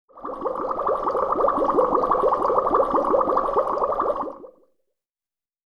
Roland.Juno.D _ Limited Edition _ GM2 SFX Kit _ 12.wav